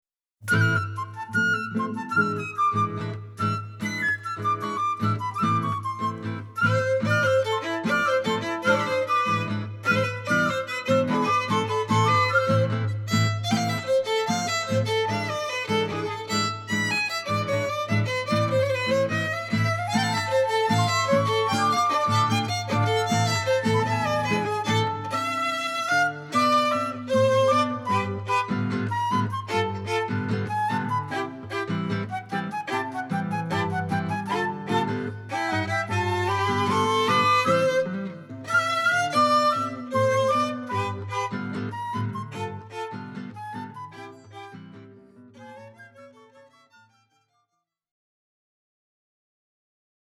registrato dal vivo: Verona, dicembre 2012